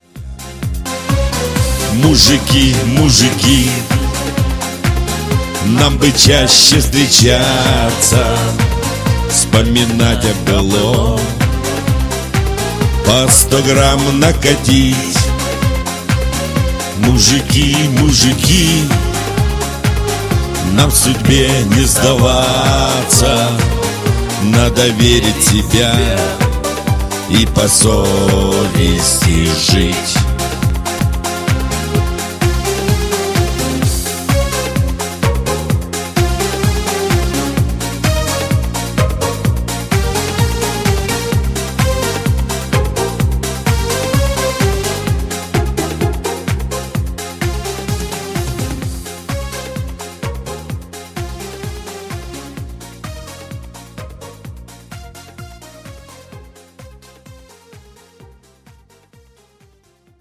• Качество: 192, Stereo
позитивные
веселые
русский шансон